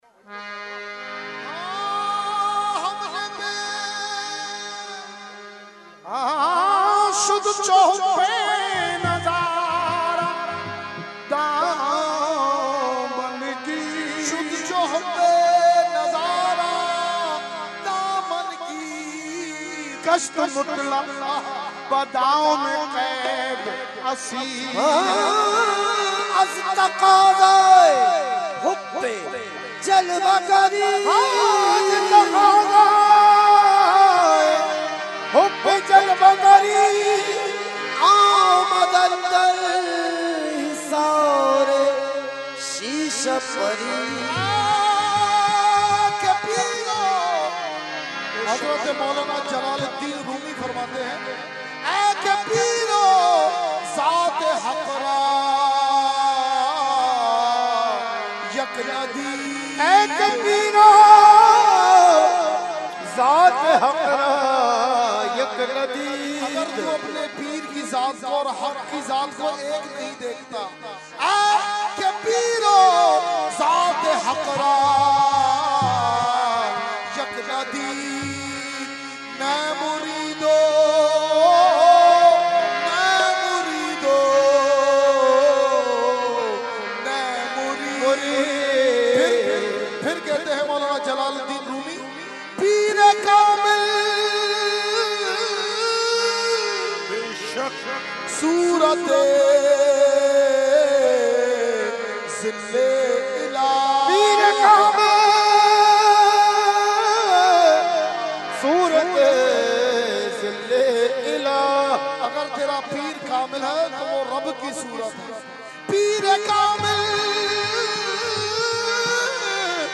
held on 21,22,23 December 2021 at Dargah Alia Ashrafia Ashrafabad Firdous Colony Gulbahar Karachi.
Category : Qawali | Language : FarsiEvent : Urs Qutbe Rabbani 2021-2